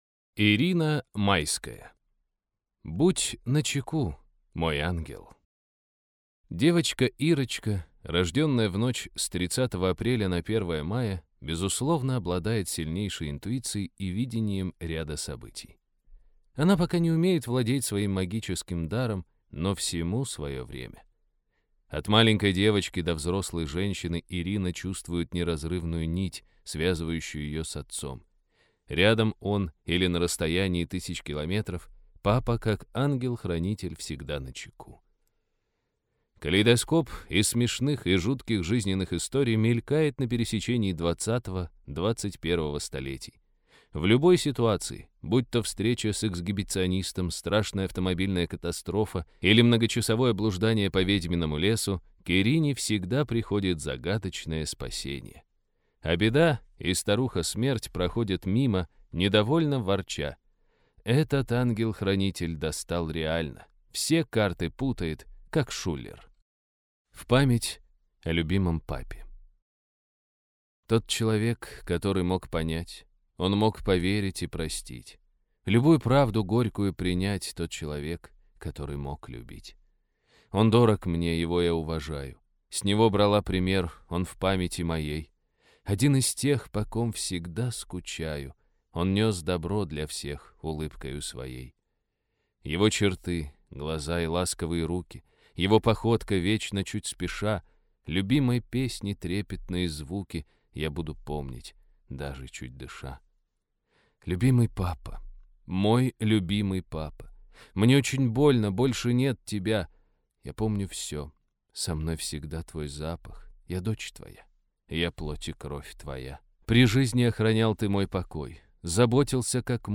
Аудиокнига Будь начеку, мой Ангел!